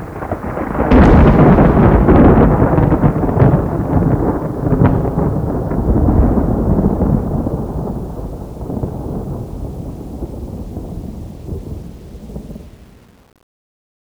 thunder.wav